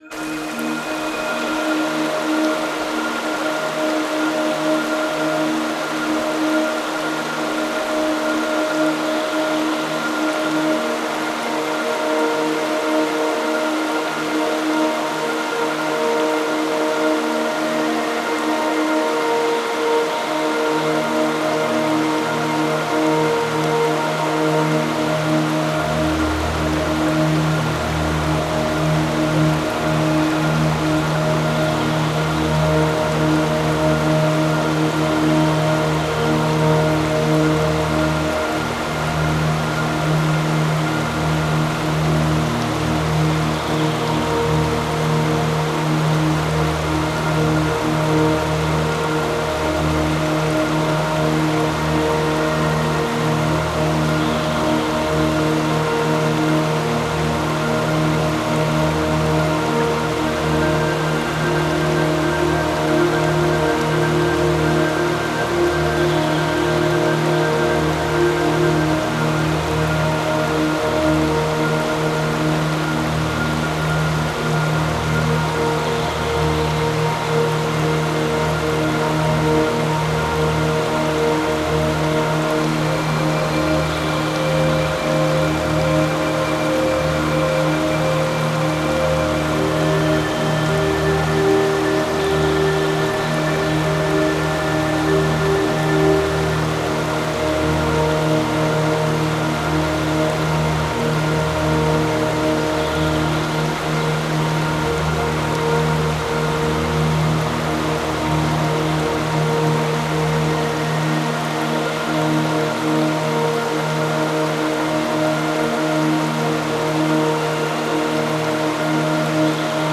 forest.opus